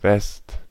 Ääntäminen
Etsitylle sanalle löytyi useampi kirjoitusasu: west West Ääntäminen UK US Tuntematon aksentti: IPA : /wɛst/ Haettu sana löytyi näillä lähdekielillä: englanti Käännös Konteksti Ääninäyte Substantiivit 1.